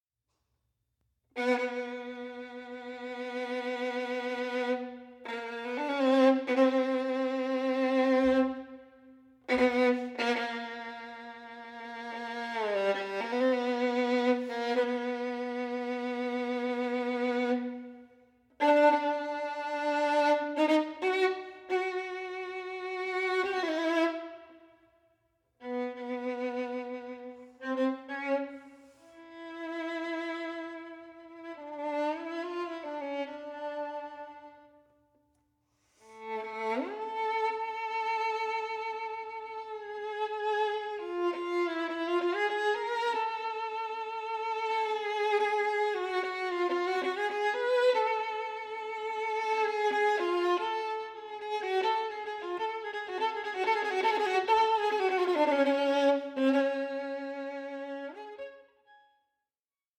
for Violin and Orchestra